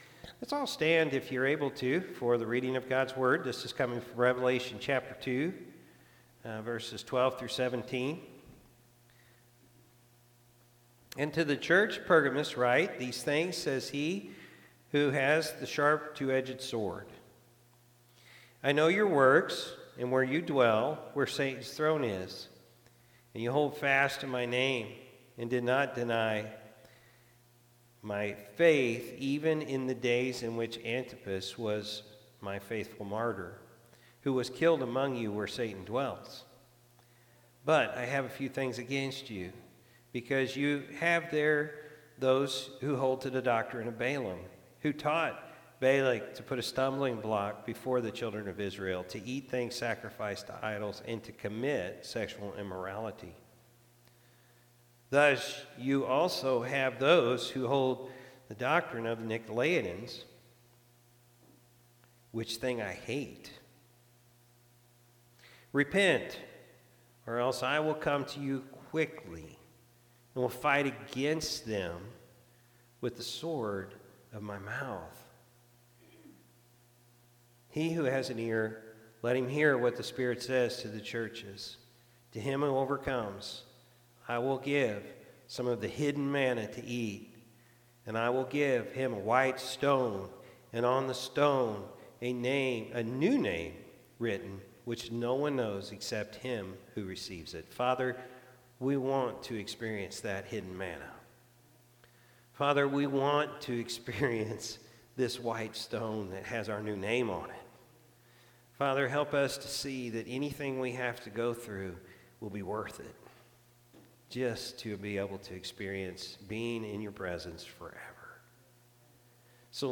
June-9-2024-Morning-Service.mp3